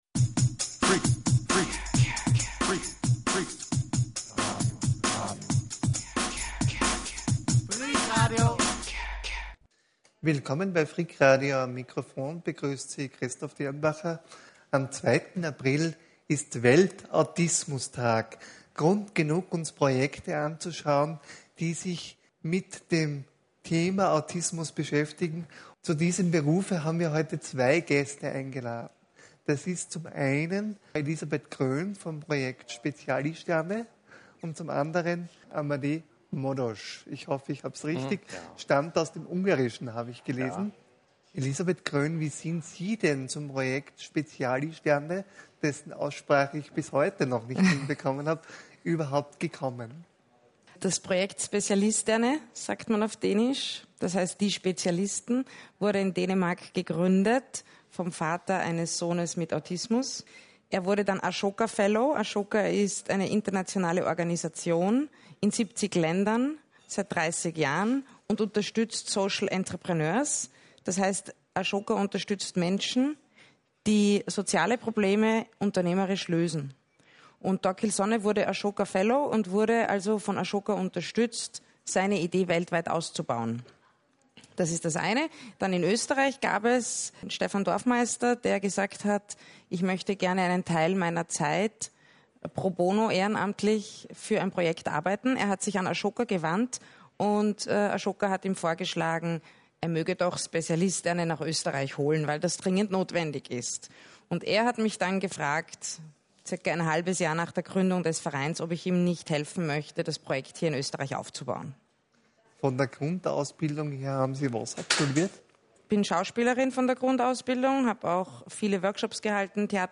Interview anlässlich des Welt-Autismus-Tags am 2. April 2016.